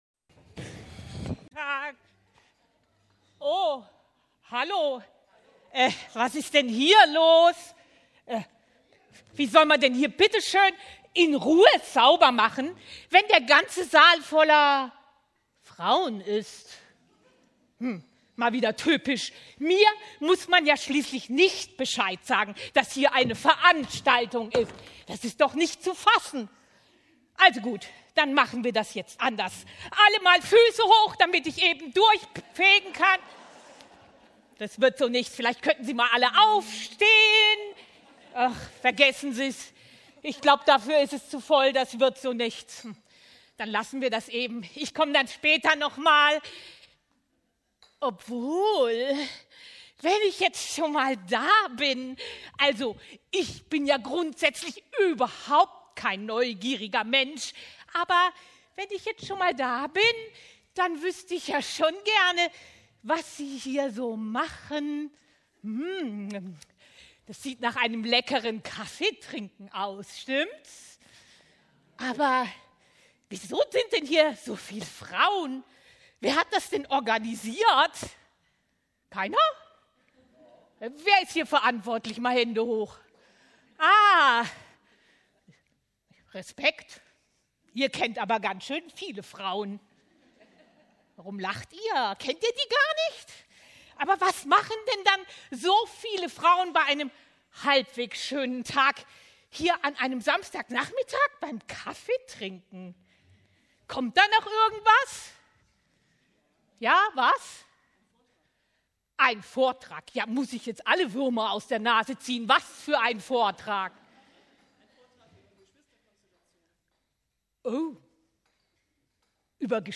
März 2024: Sketch anhören oder downloaden.